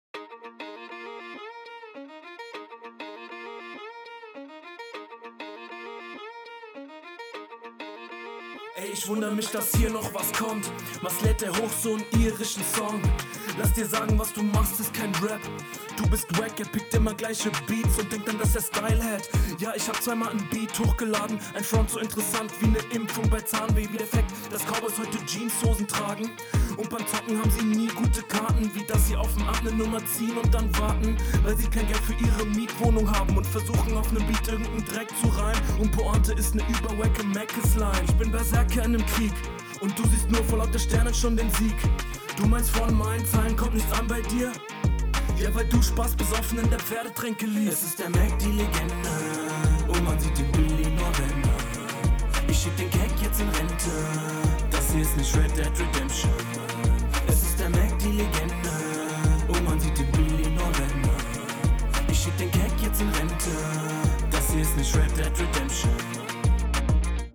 ja, vocals auf der linken seite only brechen dir halt alles, was mix/master angeht. was …